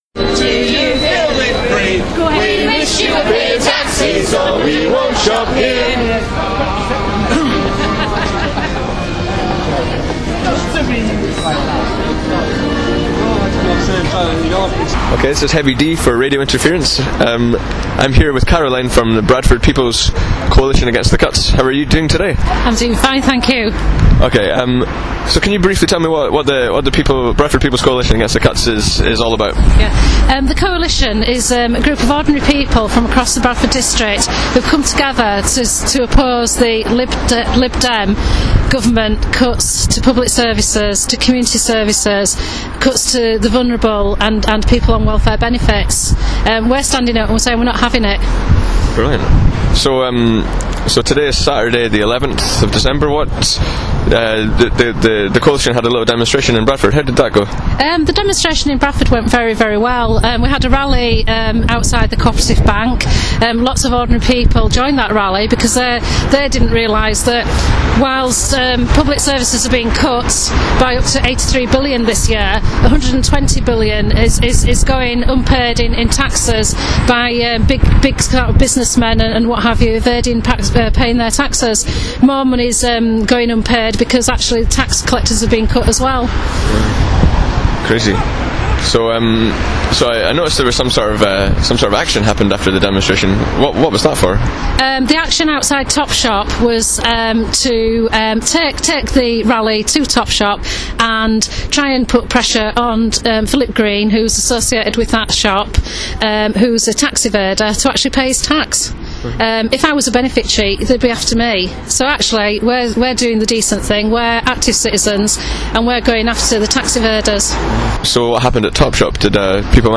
Sound bites from Ant-cuts activists on Saturday 11th of December in Bradford.